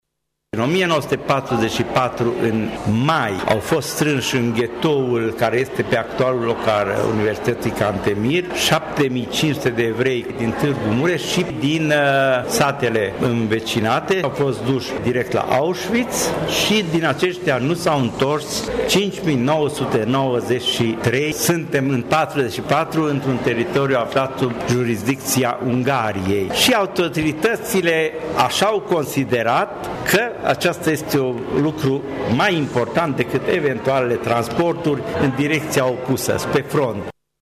Primaria Tîrgu-Mureș a organizat, azi, în Sala de Oglinzi a Palatului Culturii, o recepție comemorativă la 7 decenii de la eliberarea lagărului de exterminare de la Auschwitz.